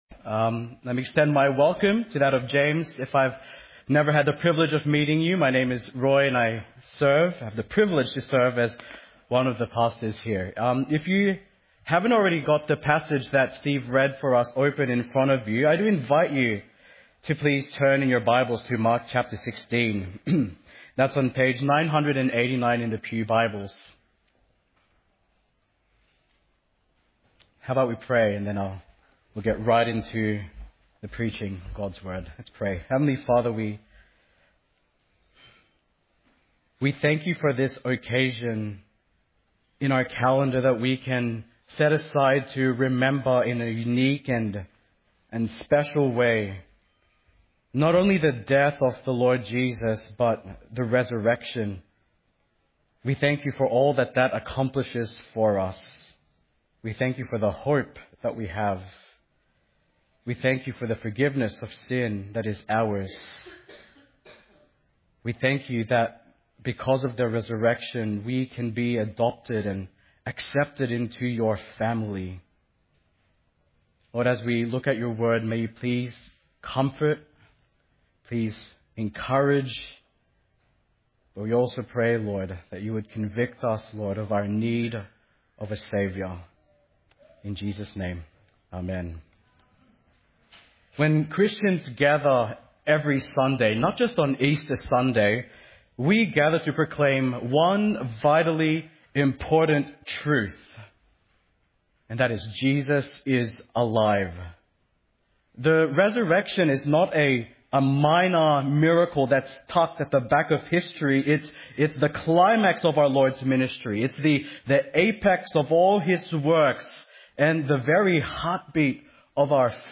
Easter